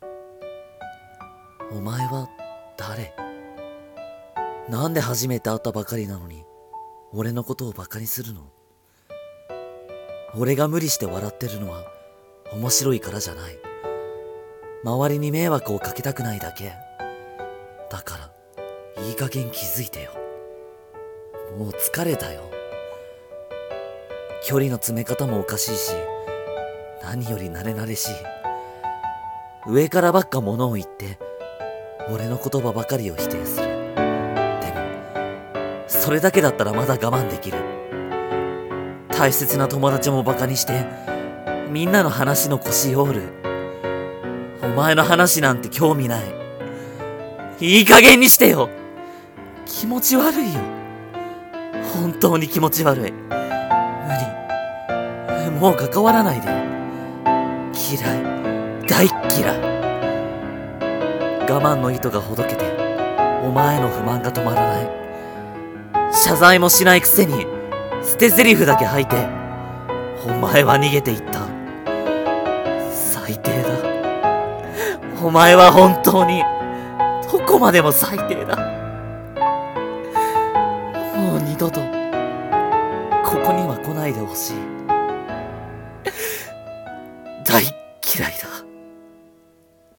声劇【大嫌い】